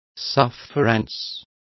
Complete with pronunciation of the translation of sufferance.